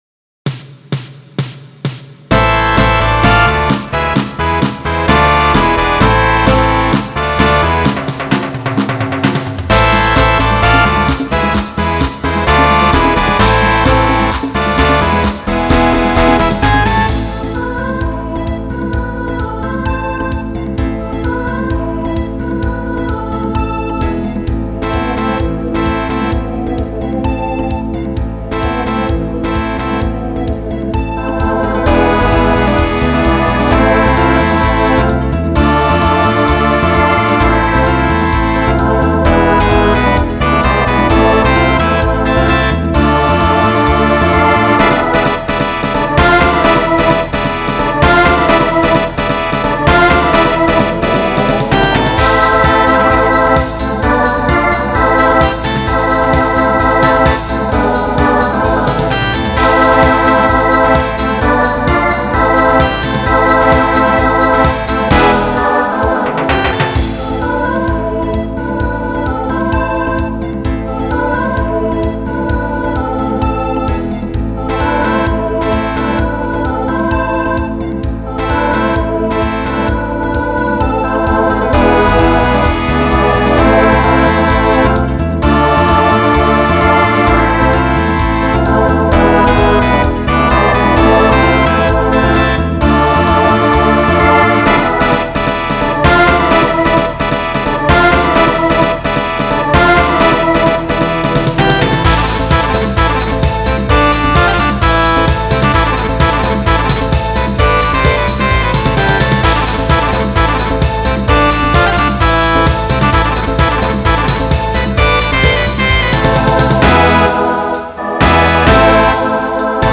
Voicing SATB Instrumental combo Genre Latin